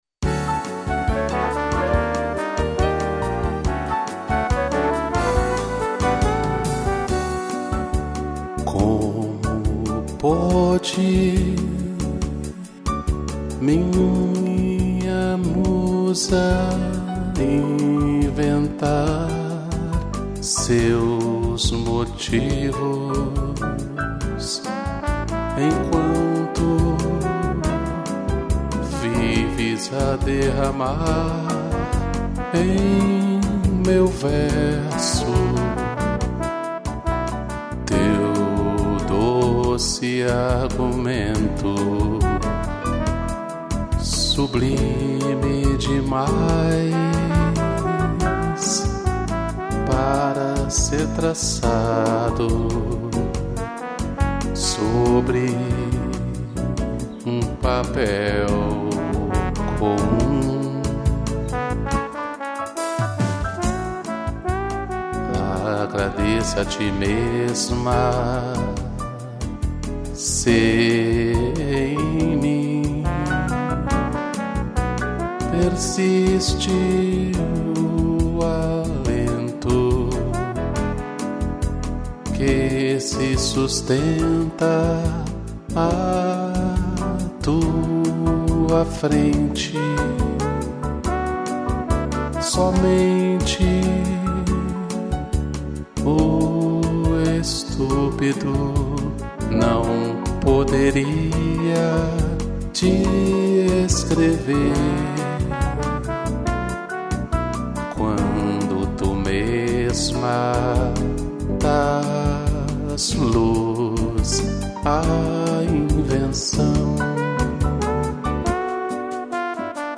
Voz
piano e trombone